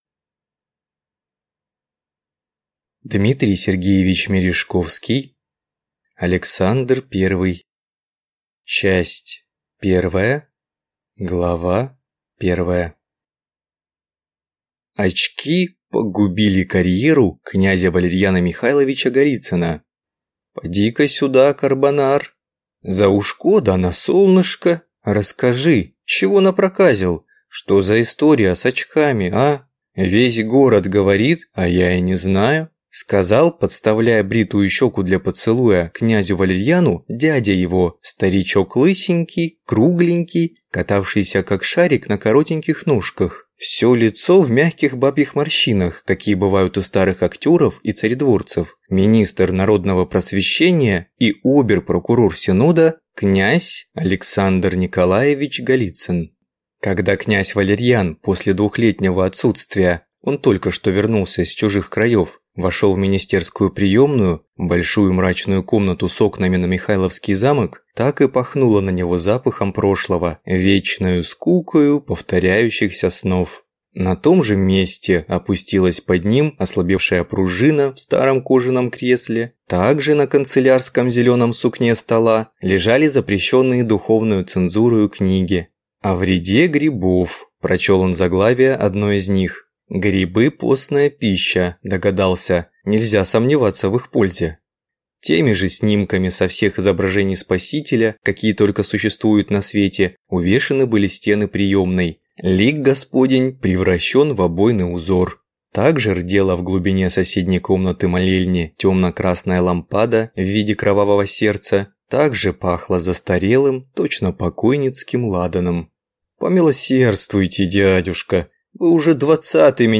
Аудиокнига Александр Первый | Библиотека аудиокниг